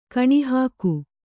♪ kaṇihāku